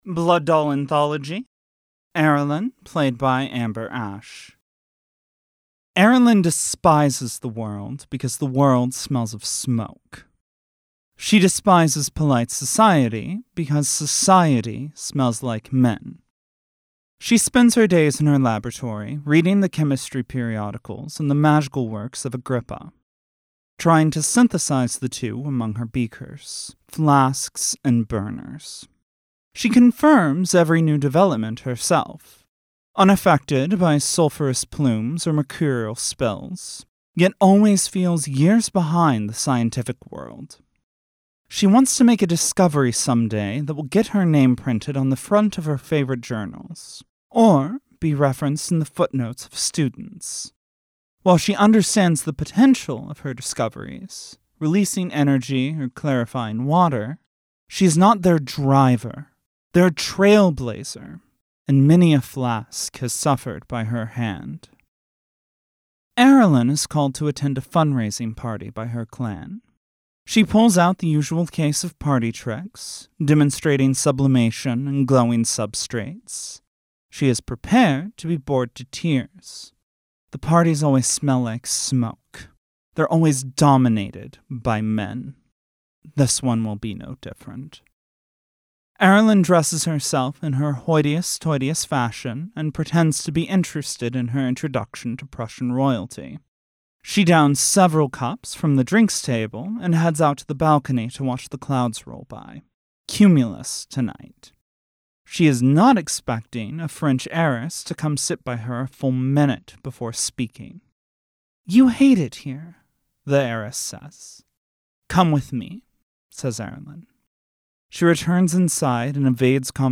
A series of vignettes about the characters in Vampire:The Masquerade: Blood Doll, read by their actors.